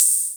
Index of /m8-backup/M8/Samples/classic machines/cr-78/Cymbal
Cymbal.wav